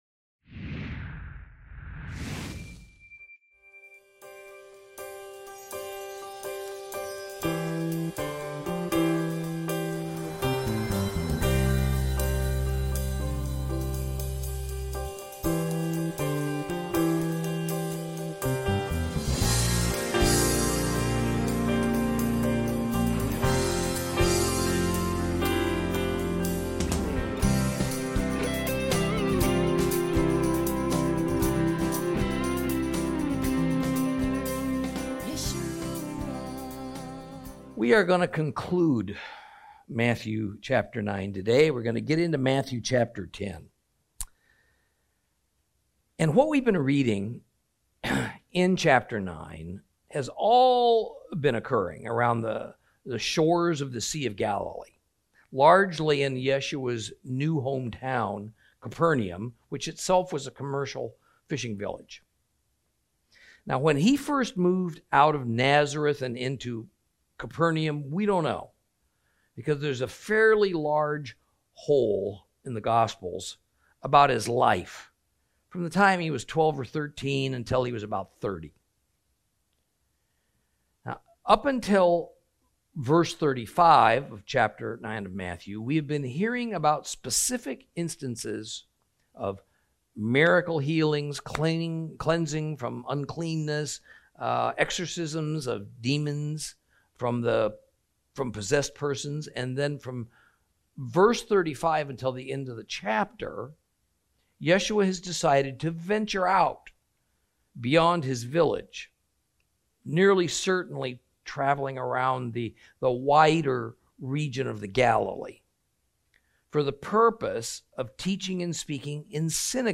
Lesson 34 Ch9 Ch10 - Torah Class